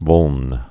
(vŭln)